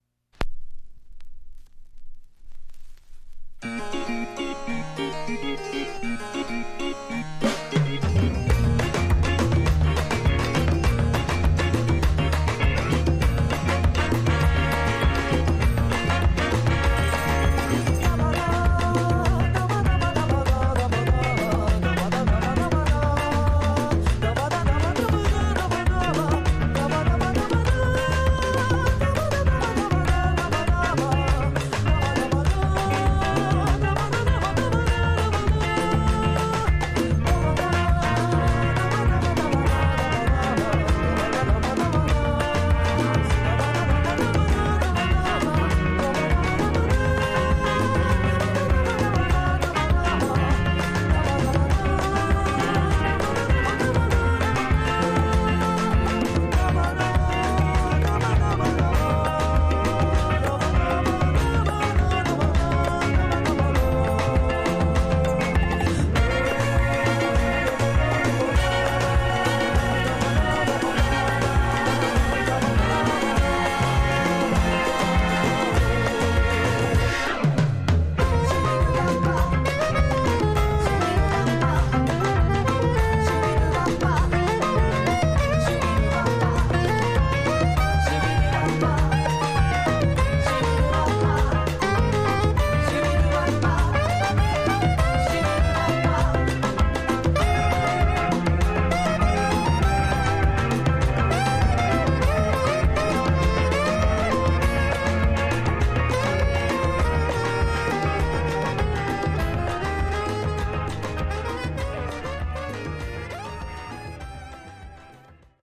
Tags: Japan , Caribbean
Haitian / French-Carib inspired ensemble